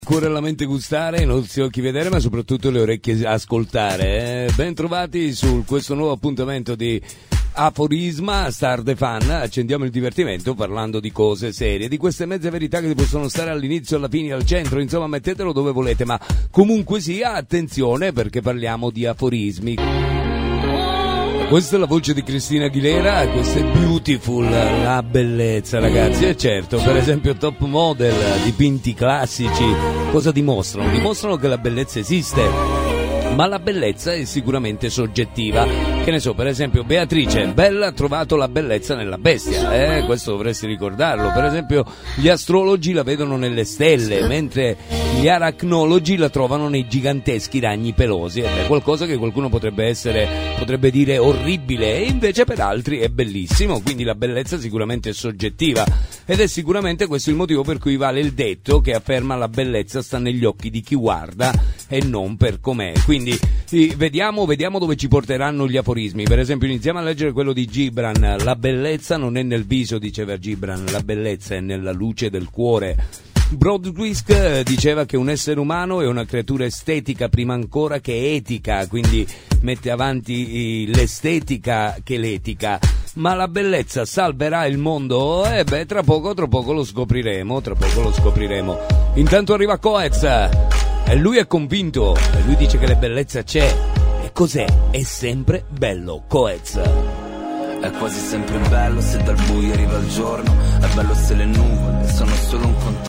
PROGRAMMI RADIOFONICI PER LA TUA RADIO
AFORISMA: programma di mezze verità, tematico, con musica di tutti i tempi che richiamano l’argomento sviluppato tra consigli ed aforismi. La personalità del conduttore e la sua voce catturano l’ascoltatore e lo fanno viaggiare nella sua vita di tutti i giorni.